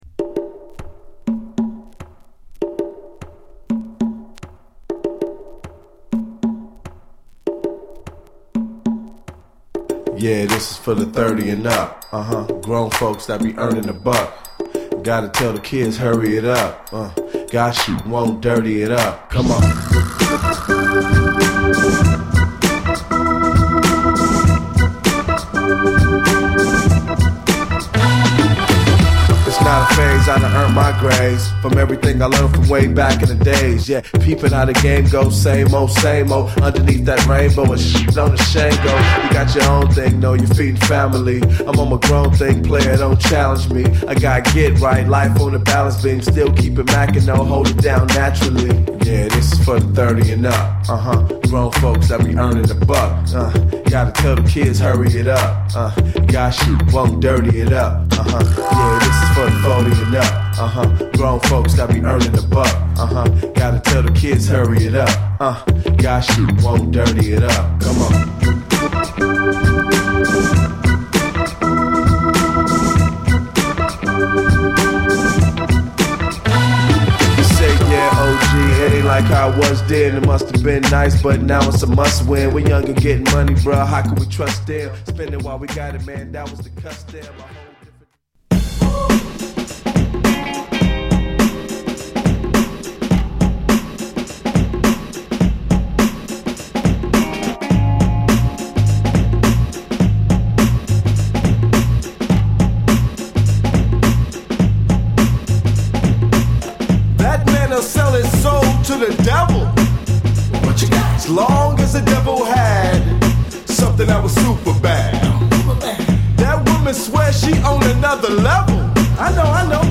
恐らく全曲生演奏によるファンク～ジャズ色強いファンキーなトラックを採用。